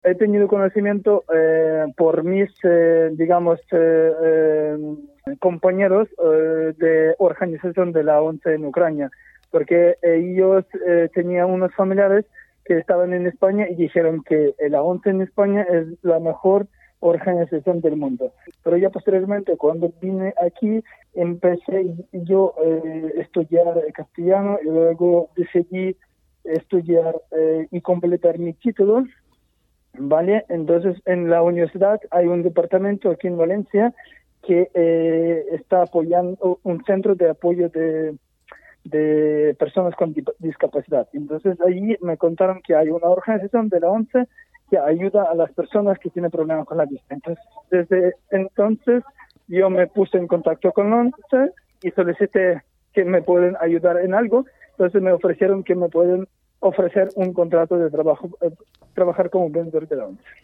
La escalada de violencia no había hecho más que empezar y multiplicarse desde que se realizara esta entrevista, vía teléfono móvil, en la tarde del jueves 10 de marzo.